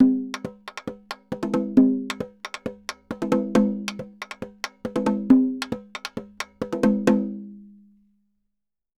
Tambora_Merengue 136-1.wav